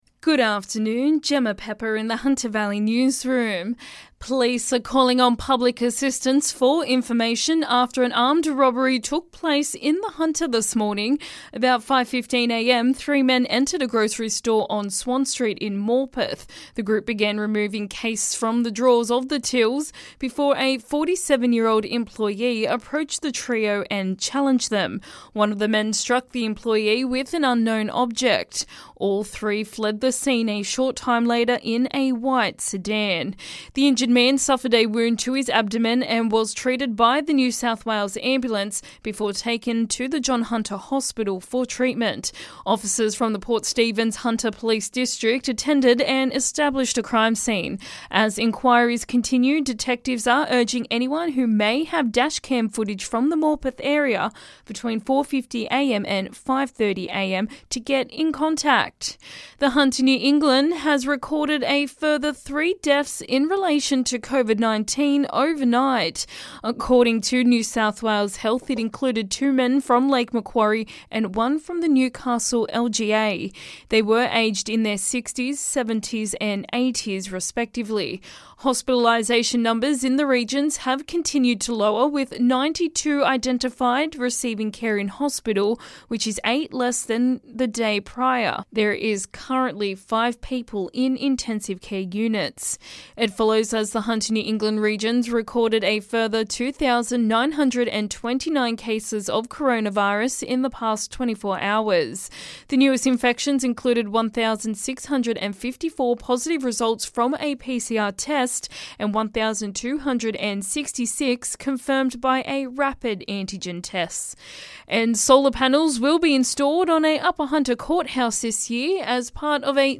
LISTEN: Hunter Valley Local News Headlines 20/01/22